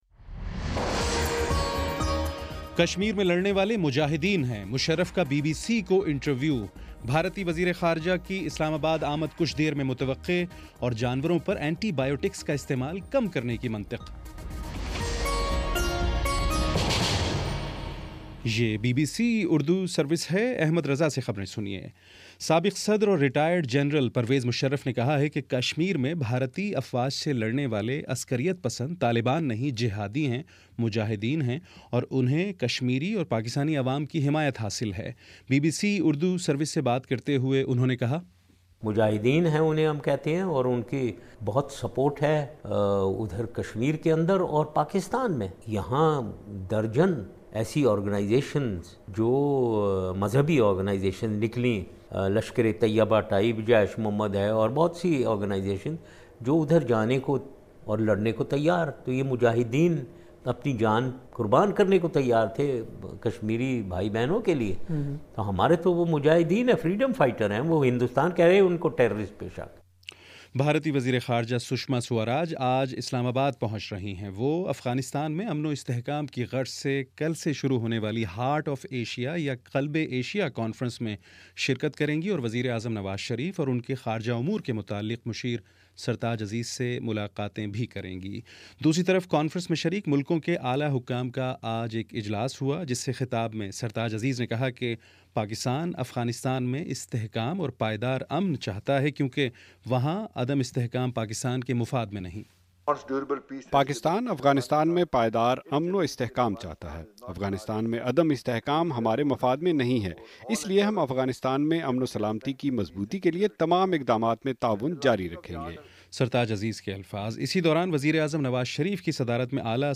دسمبر 08 : شام پانچ بجے کا نیوز بُلیٹن